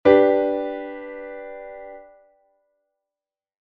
Que tipo de acorde estás a escoitar?